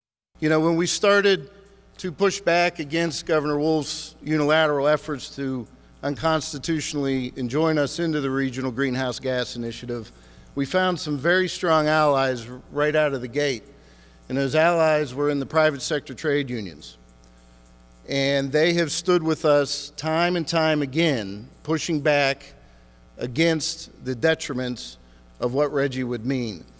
At a news conference yesterday, officials said that the permitting process in Pennsylvania has cost the state many jobs.
In remarks yesterday in the state capitol, State Senator Joe Pittman credited the private-sector trade unions for their support.